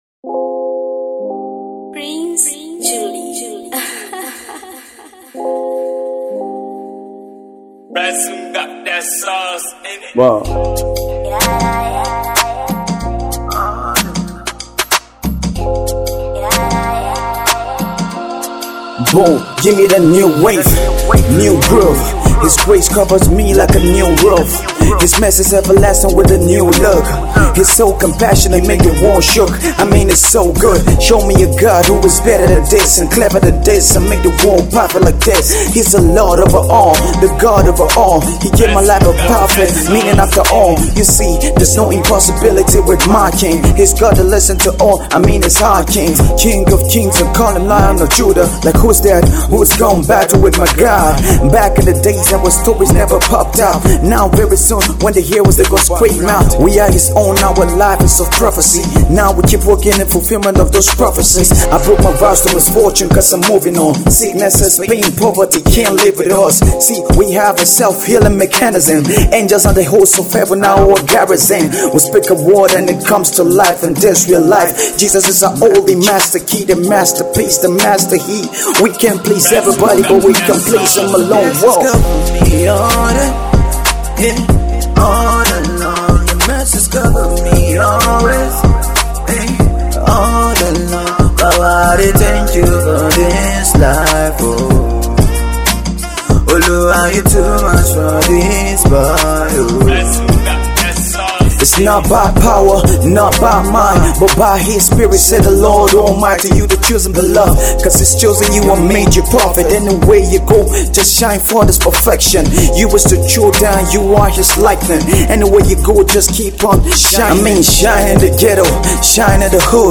gospel rapper